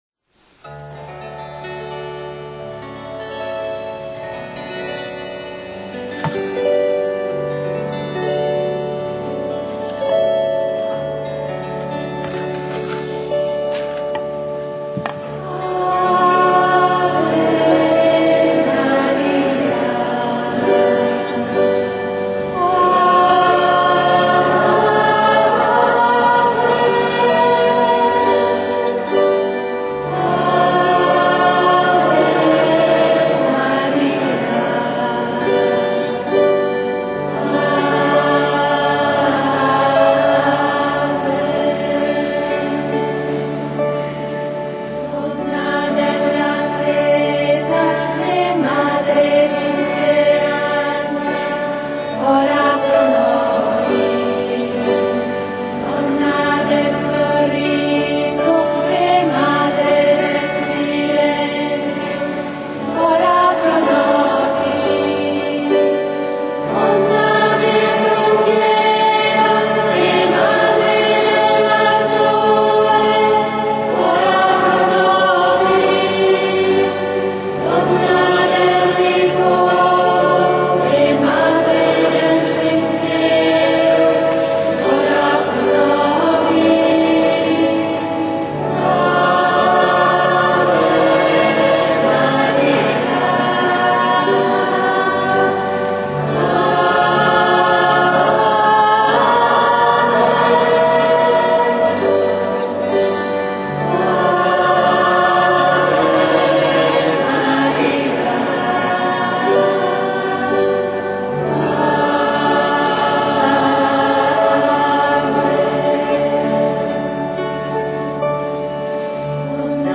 IMMACOLATA CONCEZIONE della B.V. MARIA
canto: Ave Maria